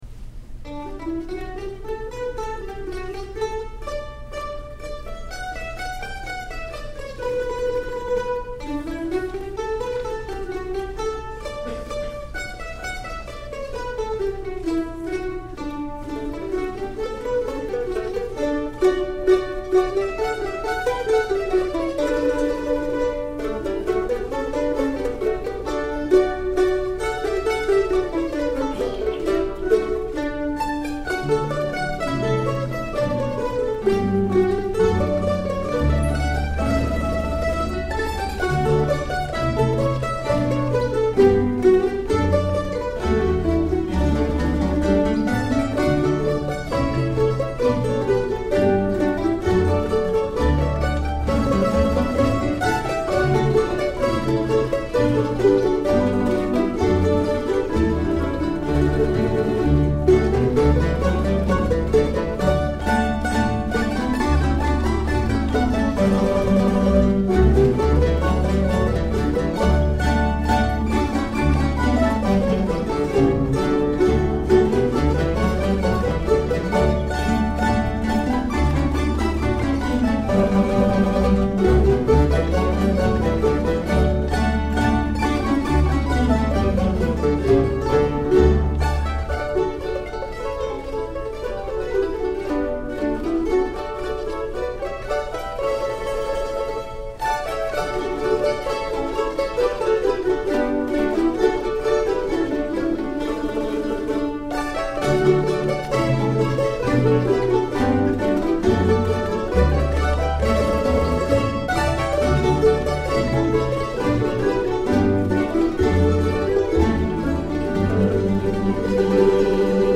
The Pittsburgh Mandolin Orchestra
performing these short arrangements of two of my tunes for mandolin orchestra at a concert in early October. These are great field recordings of a real American mandolin orchestra (nearly 40 members strong) at play.